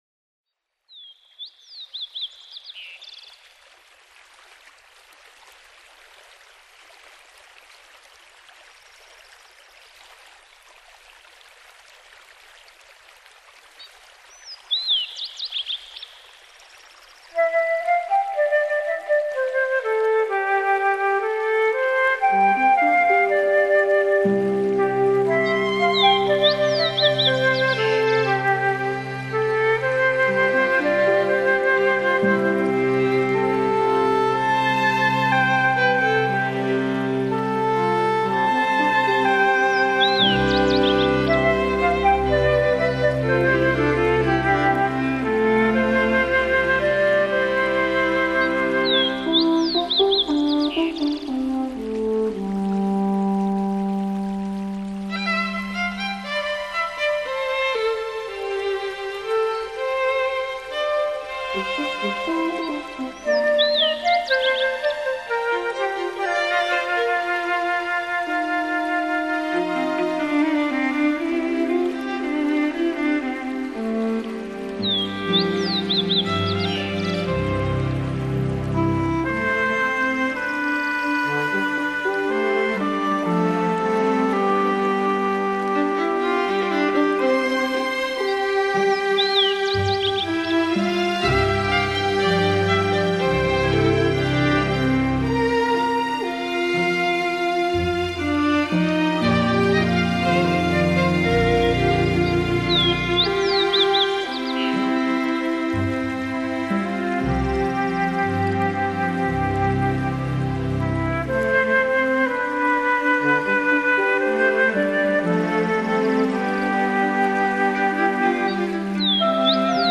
邂逅森林 情牵弦乐
在提琴的清丽弦音中编织浪漫
小提琴和中提琴的微扬音色衬著竖琴、长笛、双簧管融洽交织著，烘托出森林里各式天籁的原音之美。